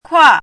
“挎”读音
kuà
国际音标：kʰuɑ˥˧;/kʰu˥
kuà.mp3